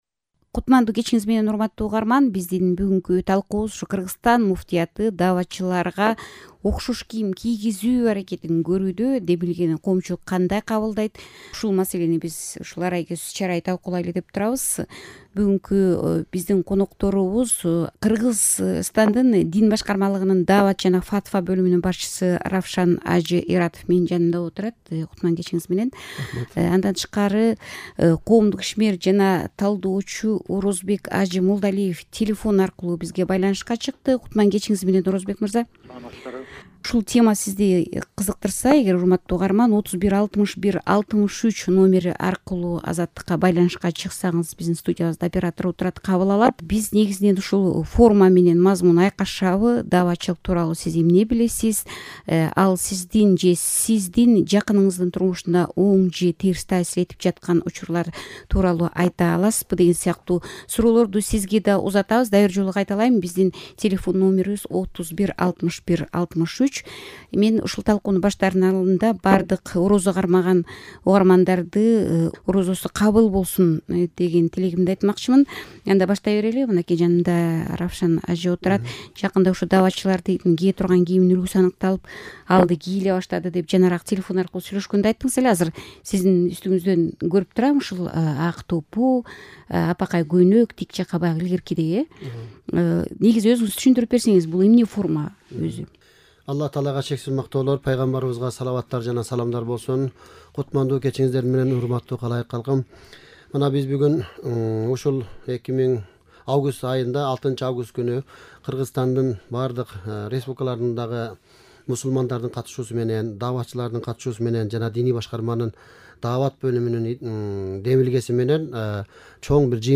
Дааватчылык: форма жана мазмун (талкууну толугу менен ушул жерден угуңуз)